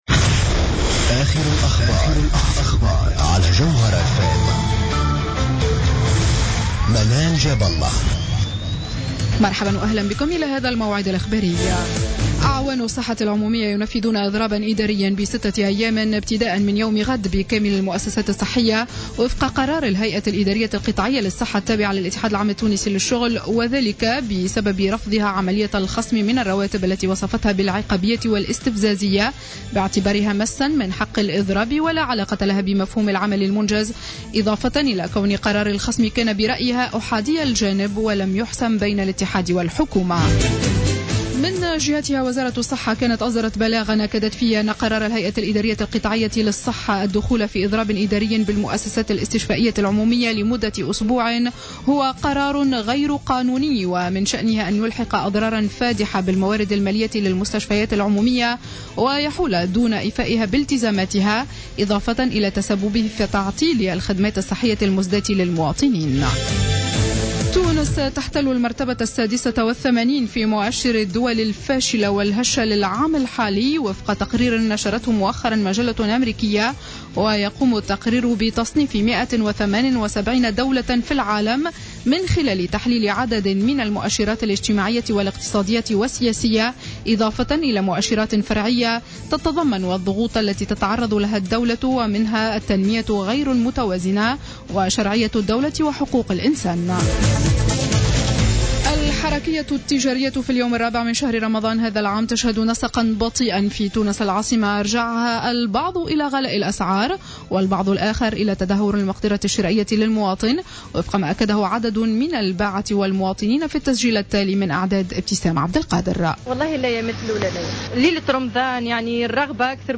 نشرة أخبار الخامسة مساء ليوم الأحد 21 جوان 2015